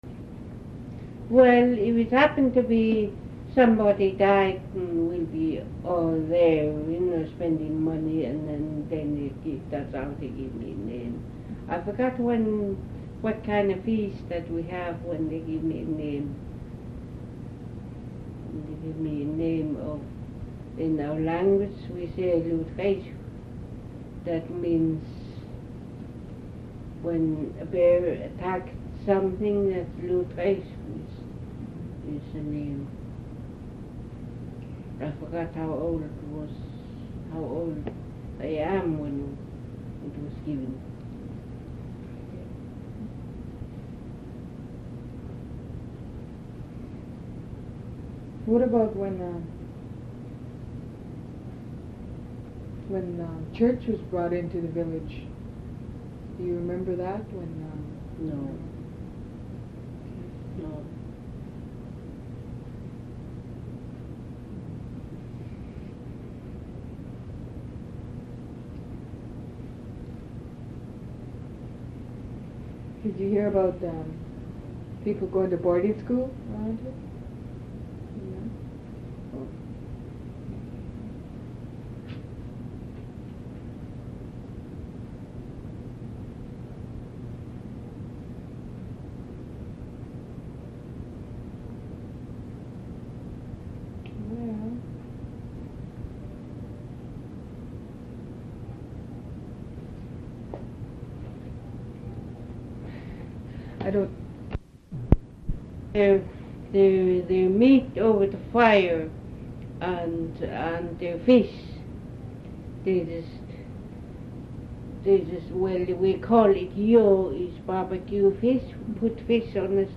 Audio non-musical
oral histories (literary works)
Hazelton (B.C.)